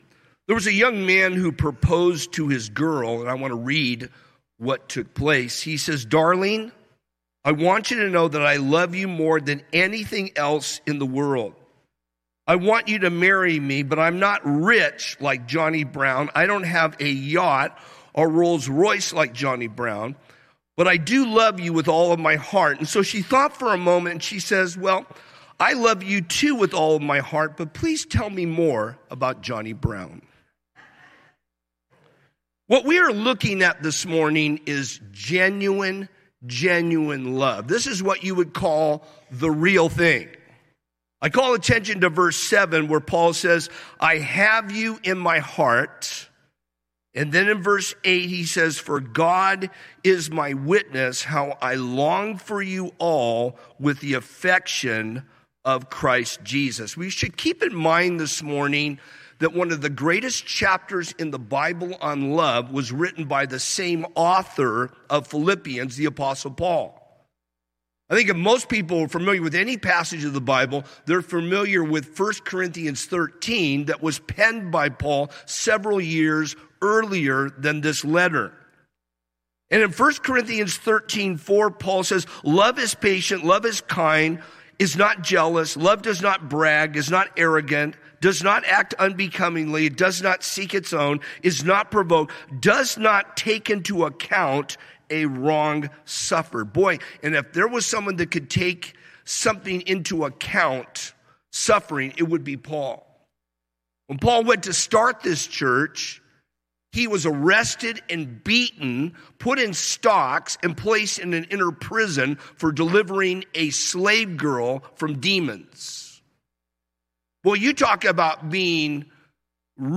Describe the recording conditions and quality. calvary_chapel_rialto_livestream-240p-online-audio-converter.com_.mp3